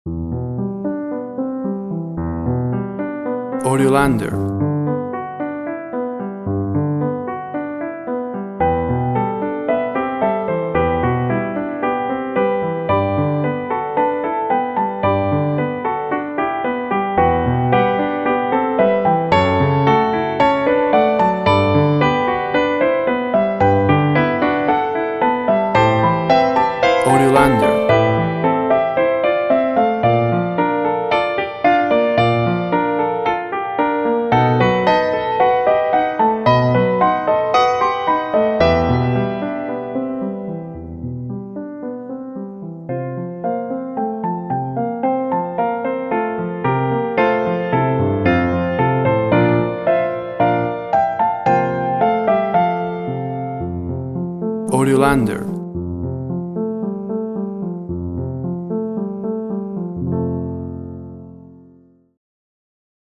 WAV Sample Rate 16-Bit Stereo, 44.1 kHz
Tempo (BPM) 112